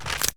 creaking_unfreeze3.ogg